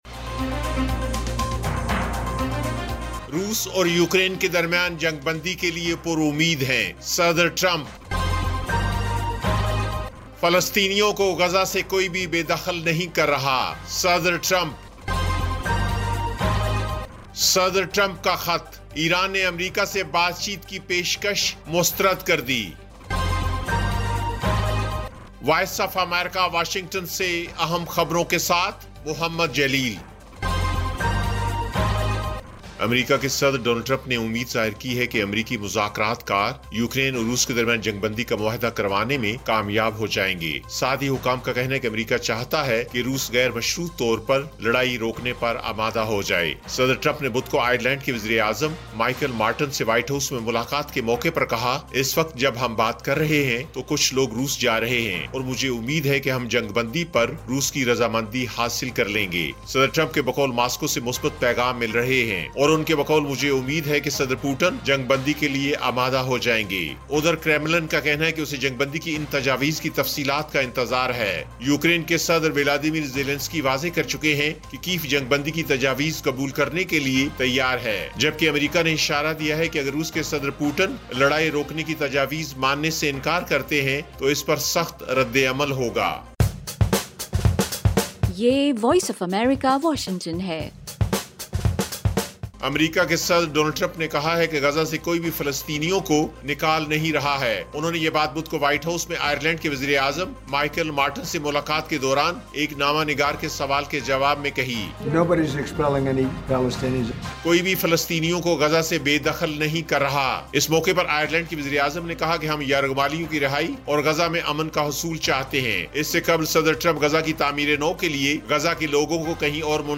ایف ایم ریڈیو نیوز بلیٹن: شام 5 بجے